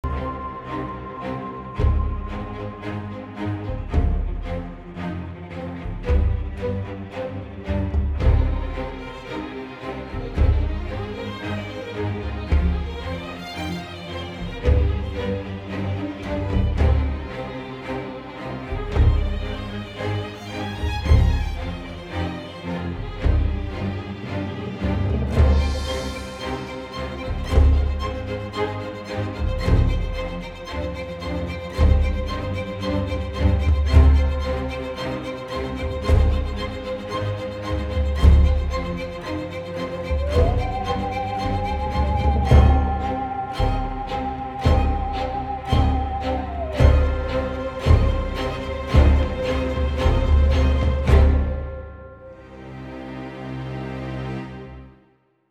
Violines
Violas
Cellos
Contrabajo
orquesta de cuerdas